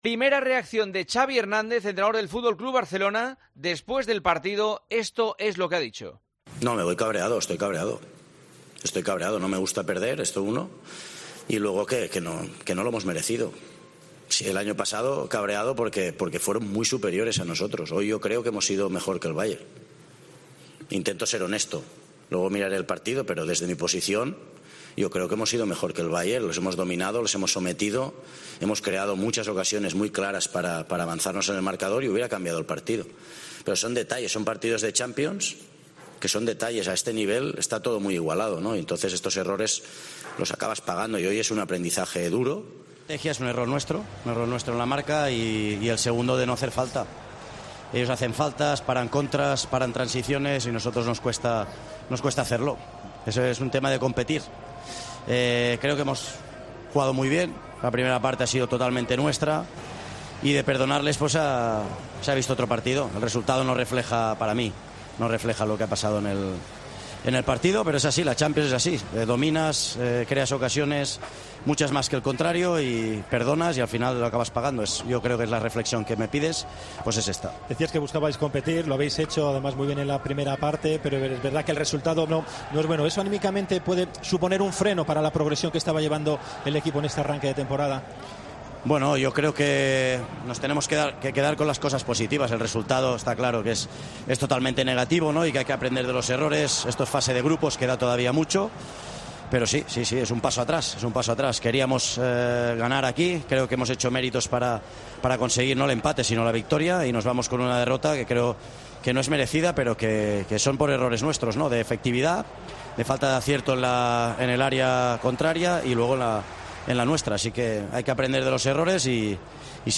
El entrenador del Barcelona ha analizado la derrota ante el Bayern de Múnich en los micrófonos de Movistar.